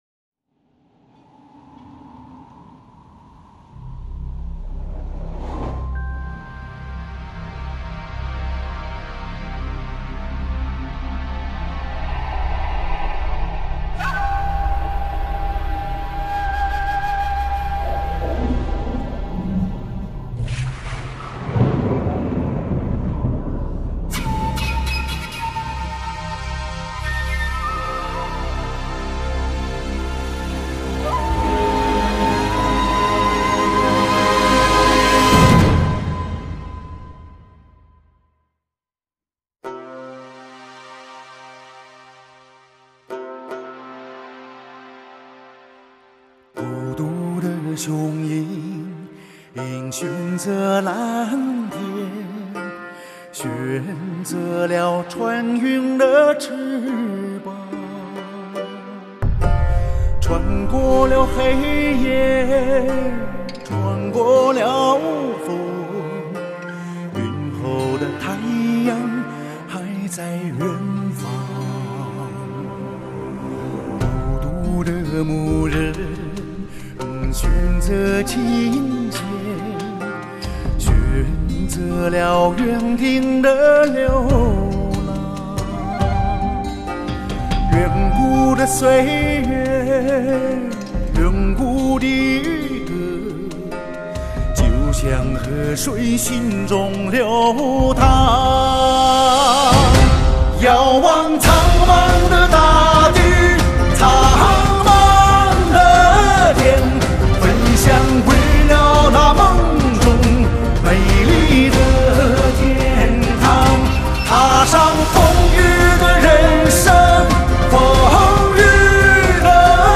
唱片类型：华语流行
运用现代通俗唱法，用音乐导演史诗的情境与故事，并塑造出自己心目中的“格萨尔